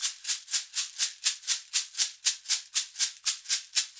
Index of /90_sSampleCDs/USB Soundscan vol.36 - Percussion Loops [AKAI] 1CD/Partition A/02-60SHAKERS
60 SHAK 01.wav